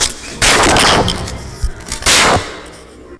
Slaves 2 74bpm.wav